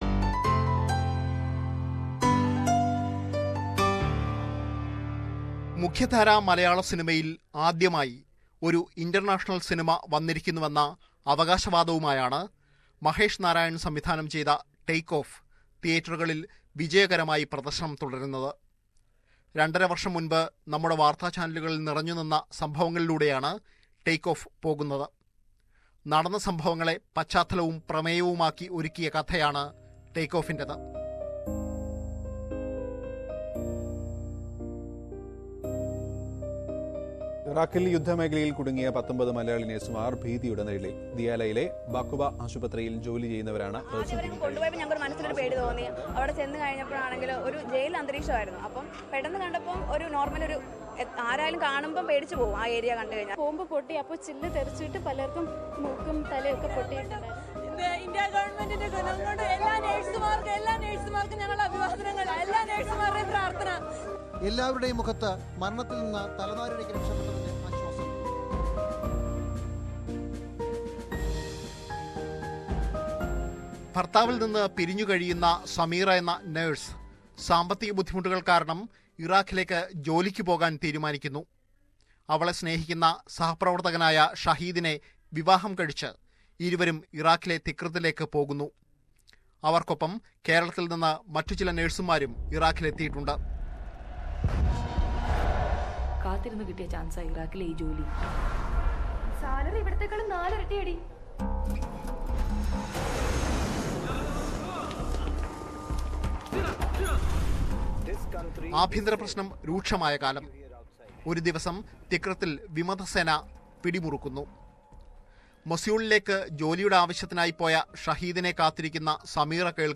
Listen to a review of the first international movie in Malayalam, based on a true incident - Take Off